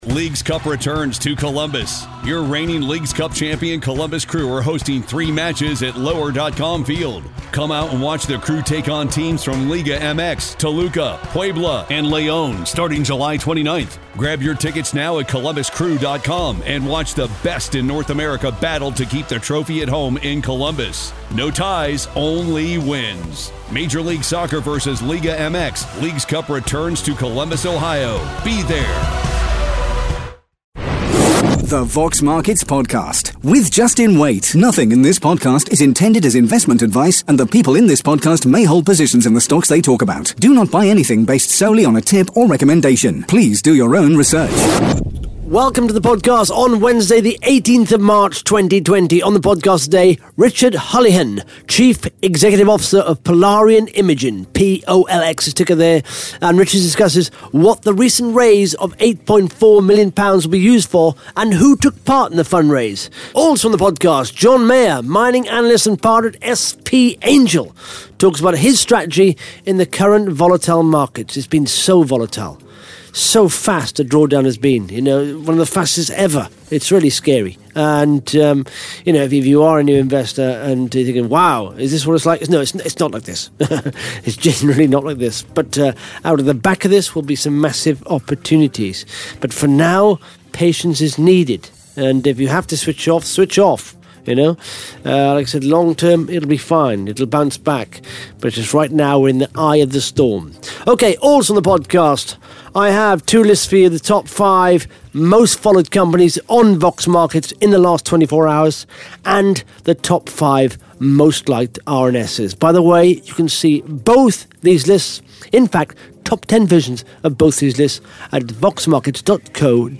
(Interview starts at 14 minutes 22 seconds) Vox Markets is revolutionising the way companies engage with shareholders and the stock market at large.